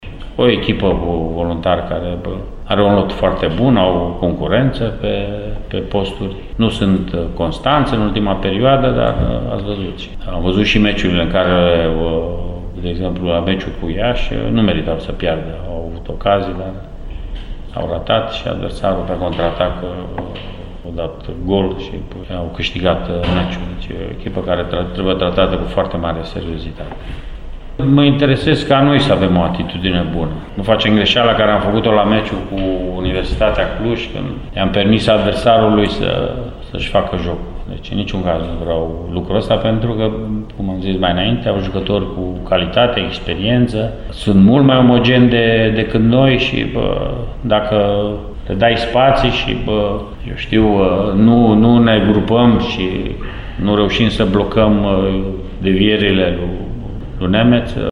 Dincolo de problemele legate de omogenitate și de inconstanță, Rednic s-a referit și adversarul din jocul de astăzi. FC Voluntari vine la Arad după succesul cu Farul din grupele Cupei României, dar în campionat a adunat șase etape în care a strâns doar un singur punct!